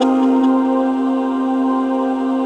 ATMOPAD27.wav